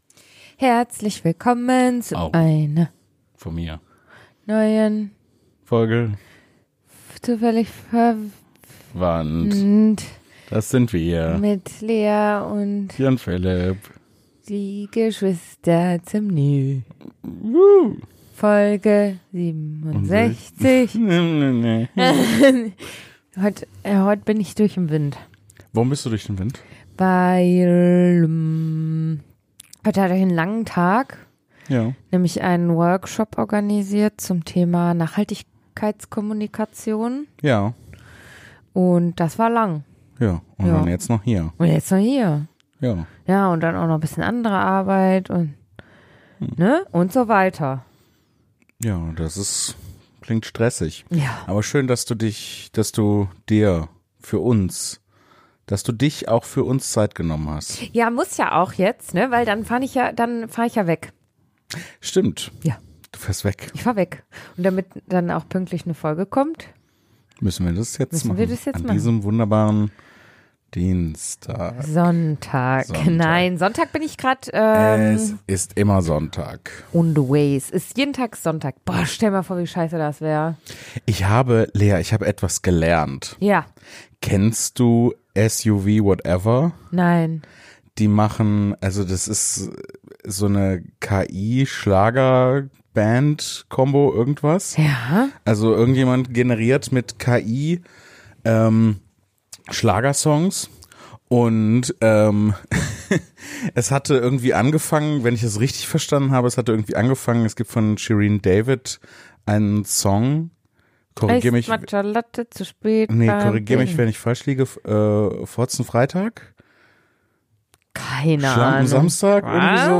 1:14:08 Play Pause 5h ago 1:14:08 Play Pause Später Spielen Später Spielen Listen Gefällt mir Geliked 1:14:08 Ein Gespräch über den Reiz (und die Abnutzung) des Free-Solo-Kletterns und die Freude am reinen Tun, ohne es jemand erzählen zu müssen.